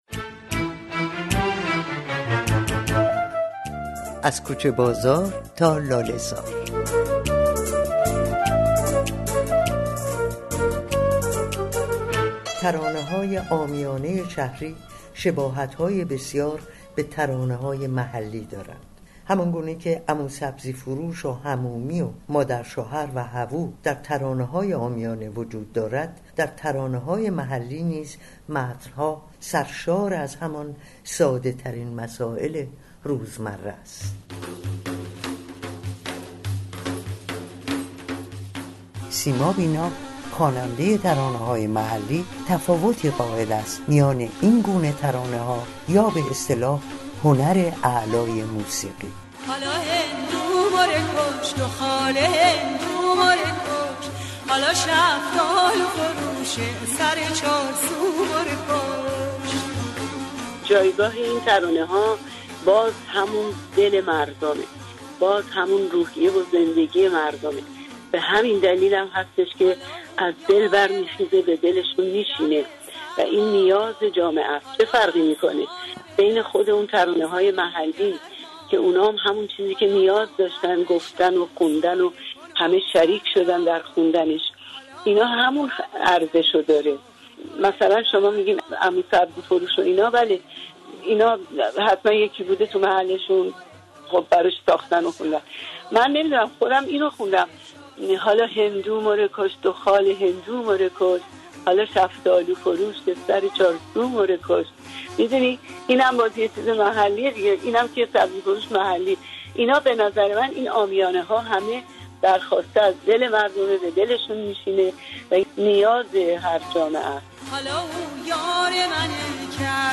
برنامه رادیویی: